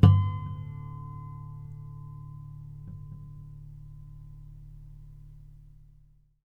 harmonic-12.wav